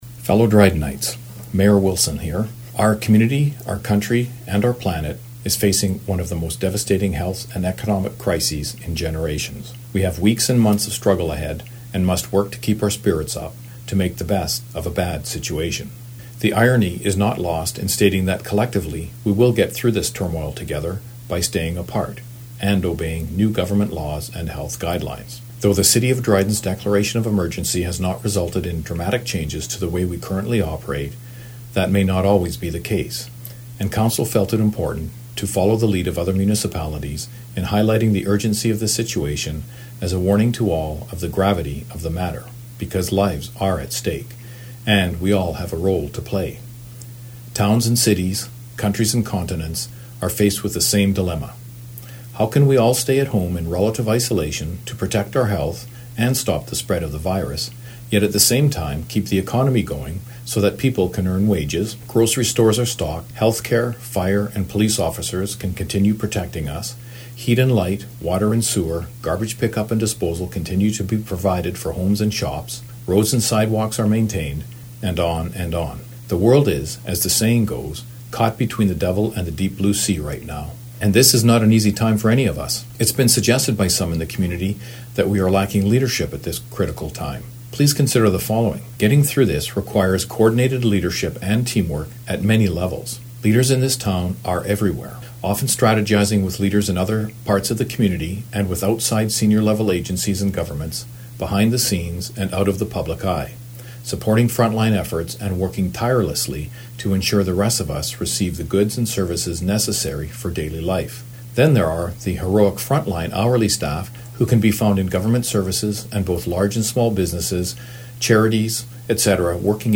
Audio: Dryden Mayor Provides COVID-19 Update
During a municipal update on the coronavirus on the CKDR Morning Show today (Monday), Greg Wilson addressed concerns surrounding leadership.